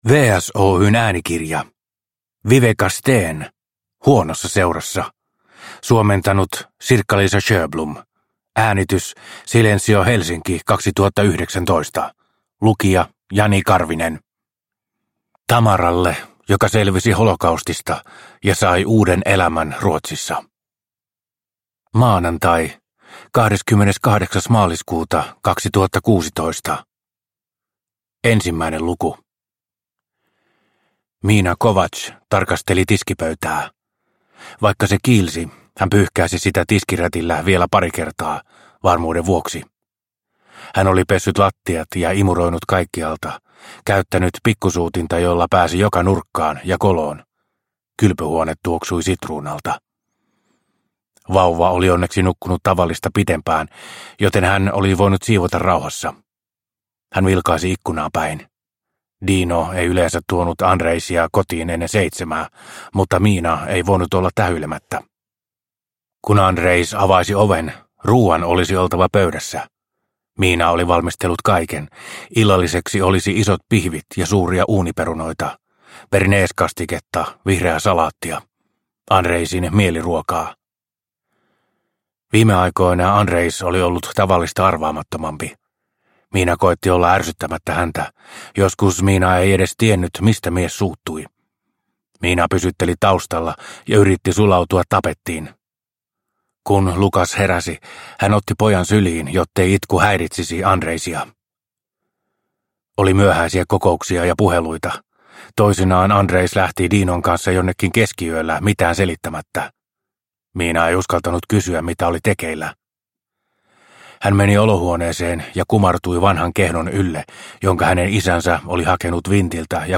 Huonossa seurassa – Ljudbok – Laddas ner